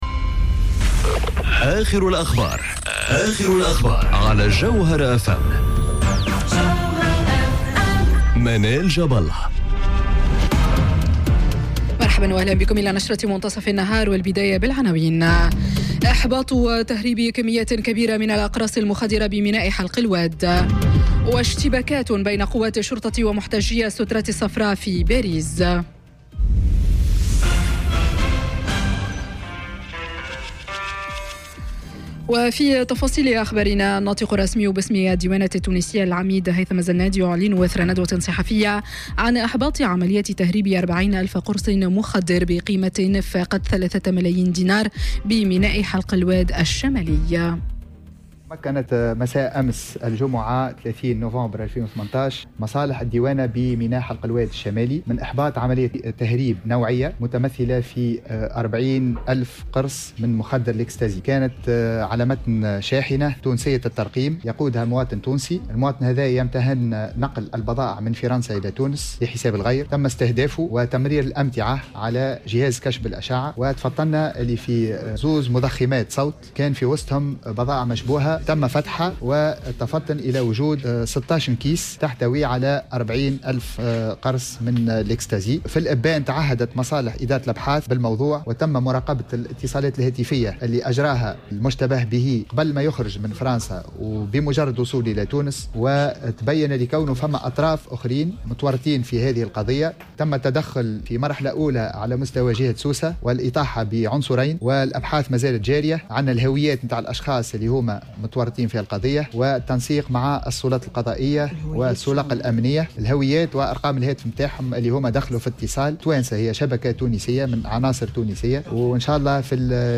نشرة أخبار منتصف النهار ليوم السبت 1 ديسمبر 2018